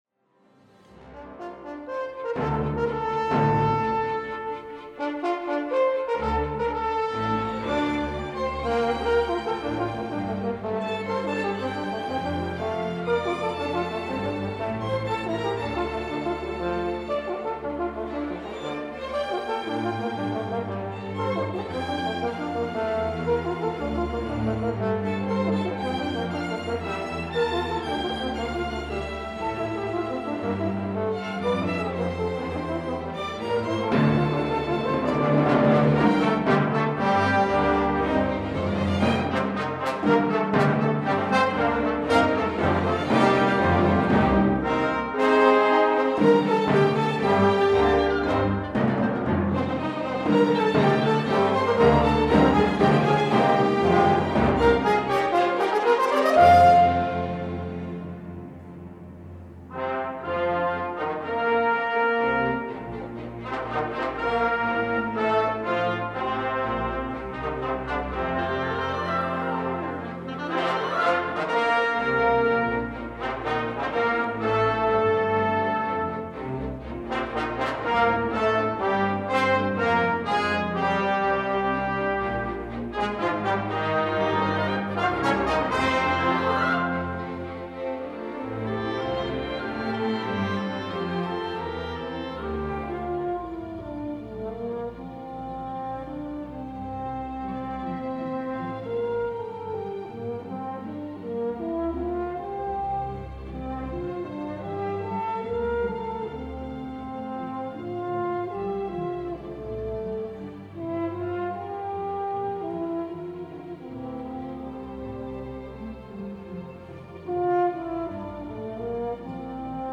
A tuneful and virtuosic new three-movement work